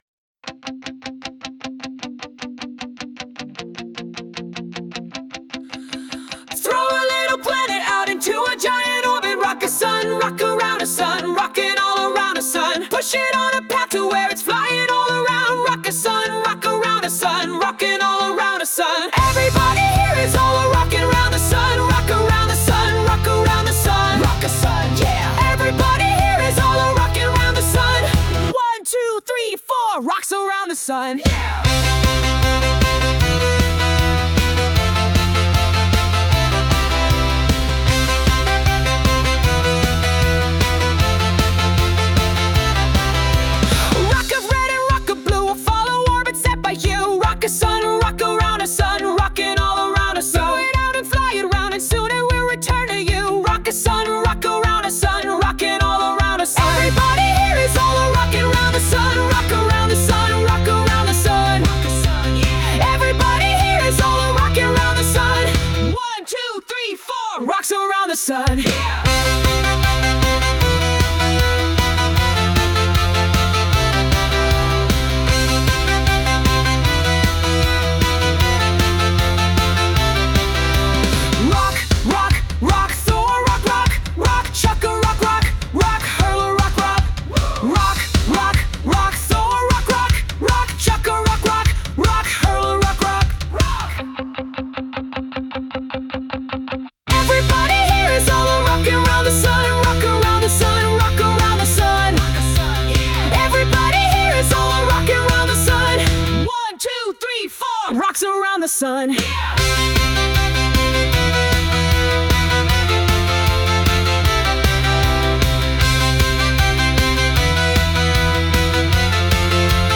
Sung by Suno
Rock_Around_the_Sun_(Remix)_mp3.mp3